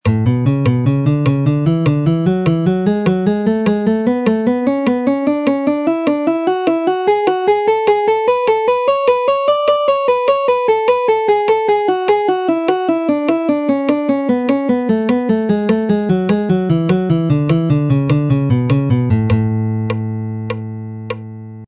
Onto the last part of this lesson which is identical to exercise #2 except it’s a sequence of three instead of four, and is played in triplet fashion rather than sixteenth notes.
This pattern simply repeats itself until you reach the first string tenth fret, at which point the whole pattern is reversed and you begin descending the scale.
Sequence of three guitar exercise